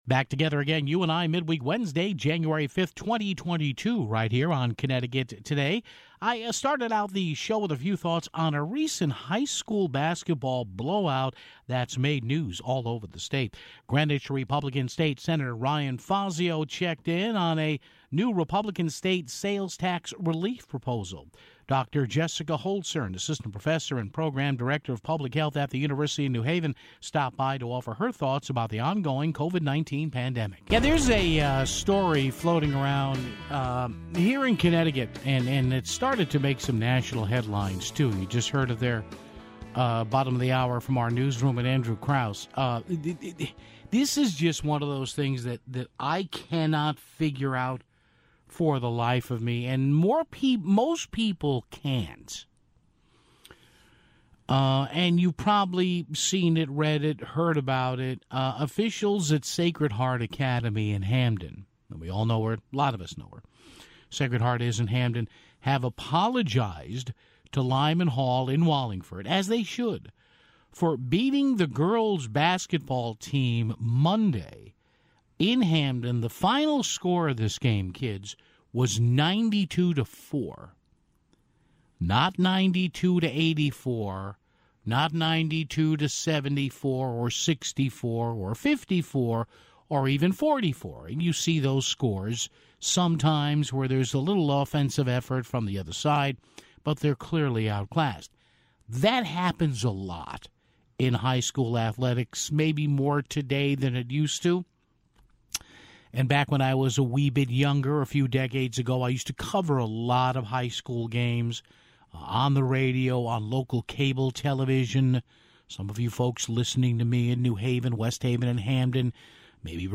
Greenwich GOP State Sen. Ryan Fazio joined us to talk about a Republican state sales tax relief proposal (9:32).